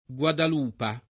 [ gU adal 2 pa ]